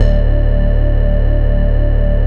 CLONE BASS 2.wav